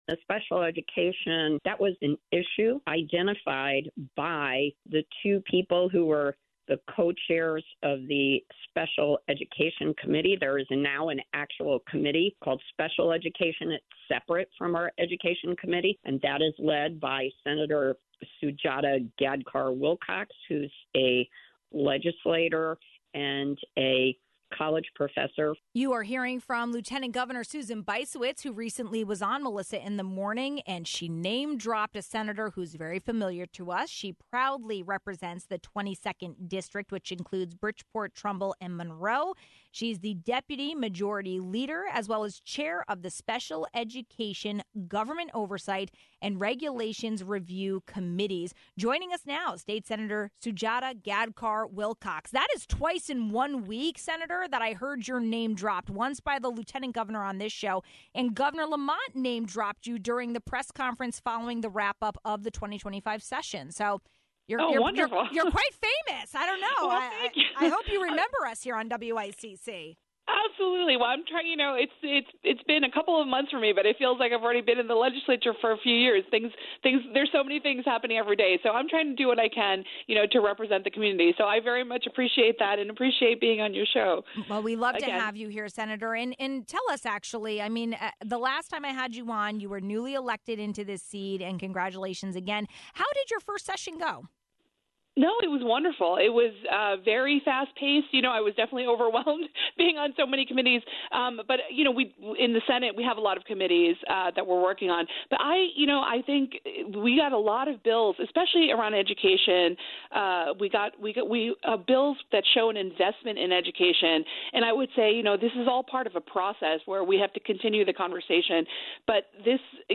There was a strong focus on investing in special education for the state during the 2025 legislative session. We spoke with the chair of the Special Education Committee, Senator Sujata Gadkar-Wilcox representing the 22nd District (Bridgeport, Trumbull, Monroe), about what exactly passed.